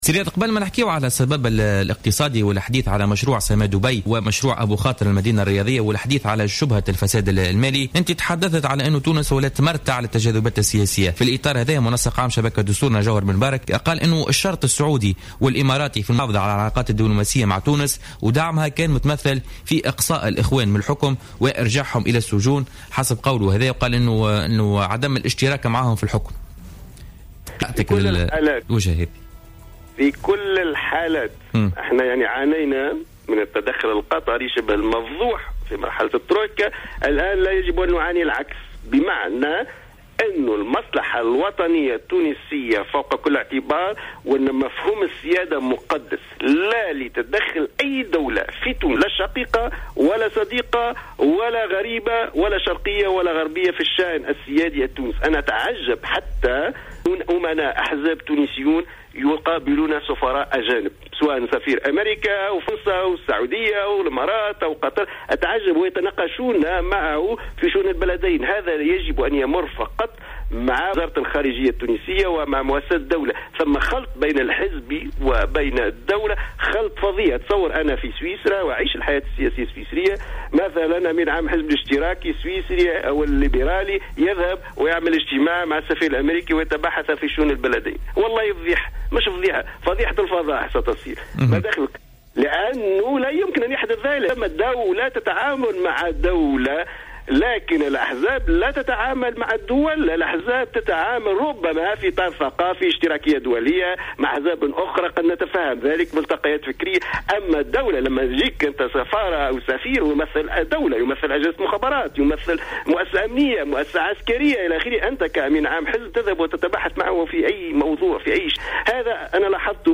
تصريح للجوهرة أف أم في برنامج بوليتكا